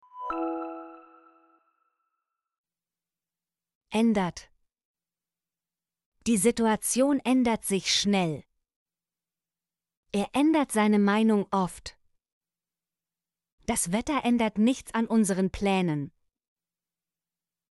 ändert - Example Sentences & Pronunciation, German Frequency List